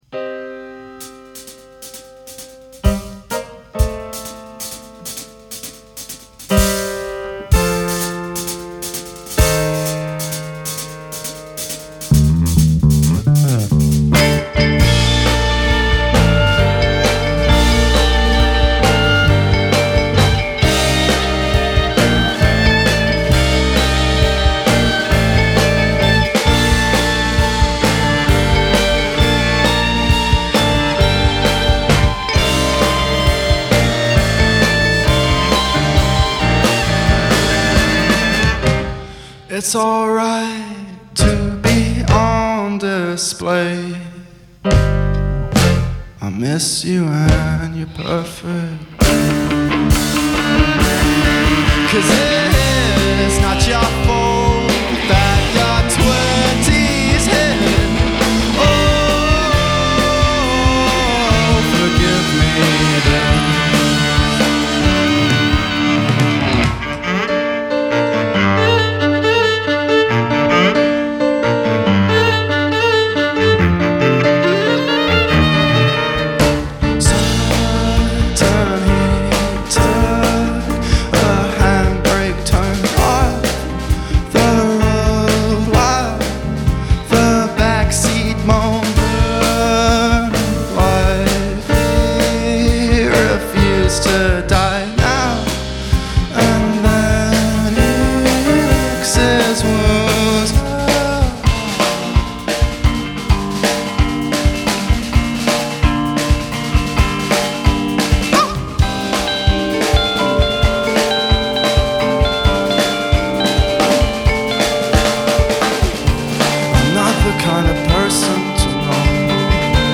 is a seven-piece band (with strings)
London Prog scene